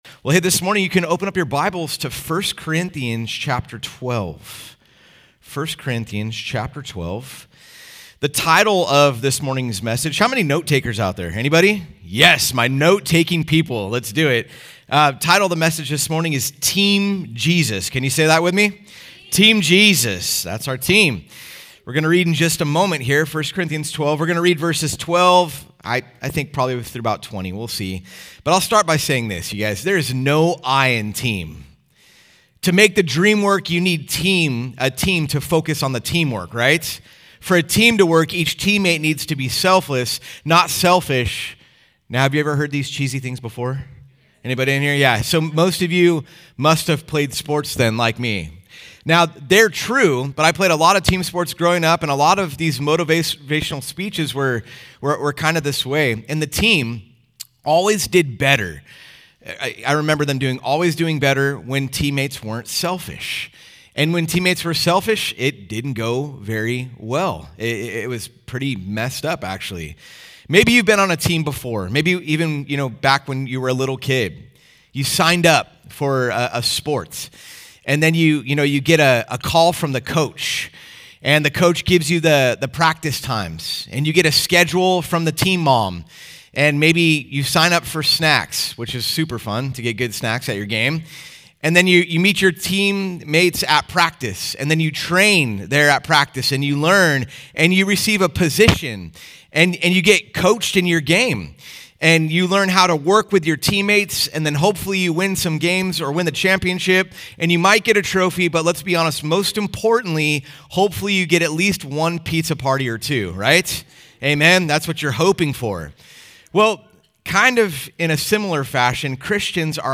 Home » Sermons » 1 Corinthians 12: Team Jesus
Conference: Worship Conference